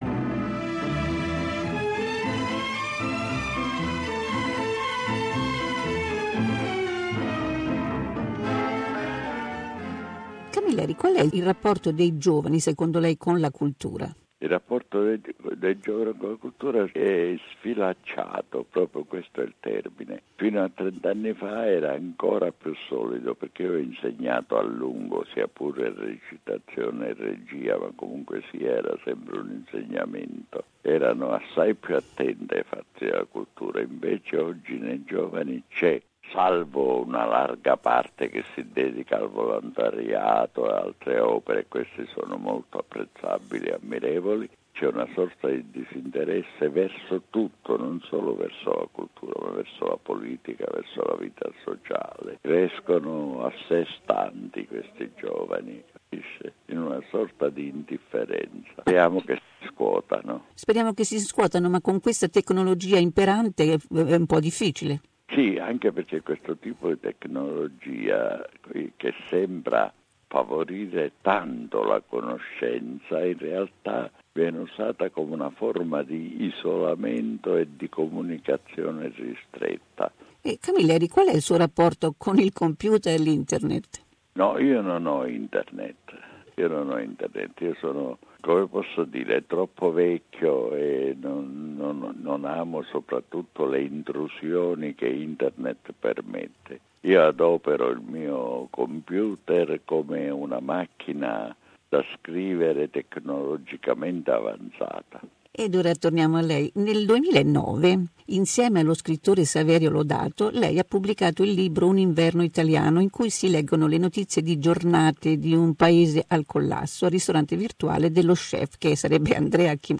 Andrea Camilleri, intervista esclusiva - 2a parte